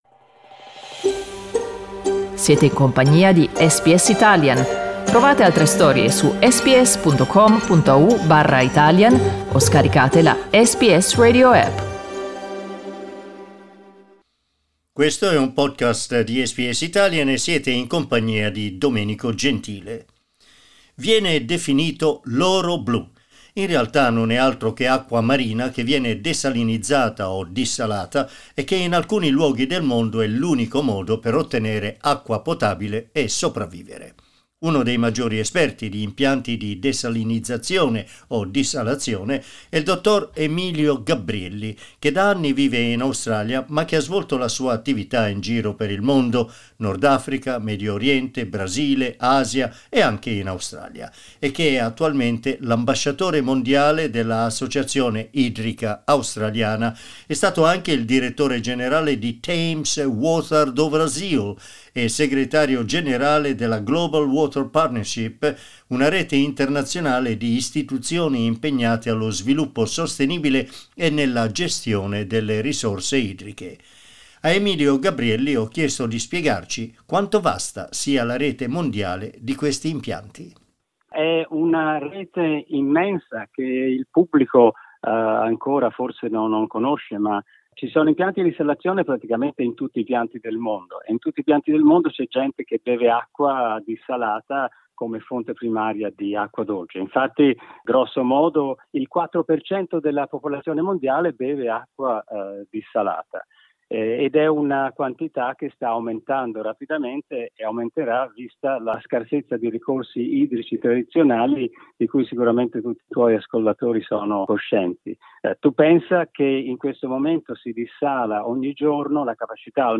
Ascolta l'intervista: LISTEN TO Acqua desalinizzata, una risorsa preziosa SBS Italian 14:24 Italian Le persone in Australia devono stare ad almeno 1,5 metri di distanza dagli altri.